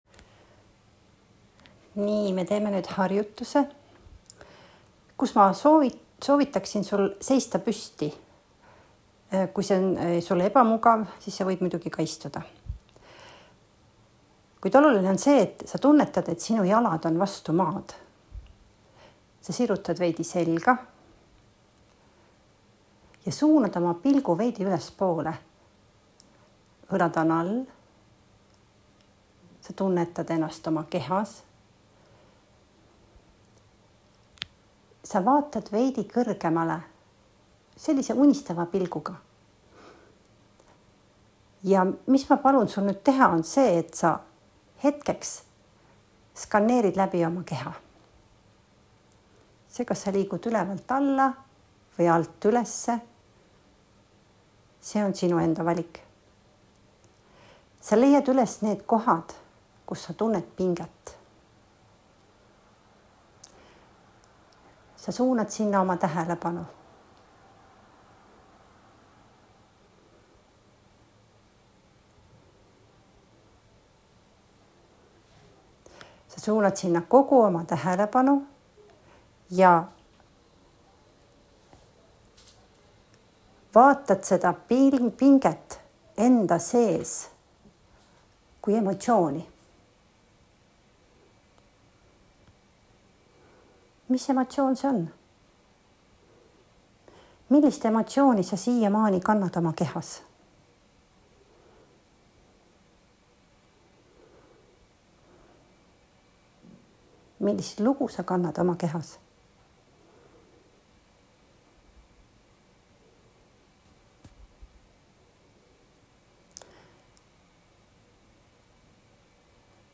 Emotsioonide-meditatsioon.m4a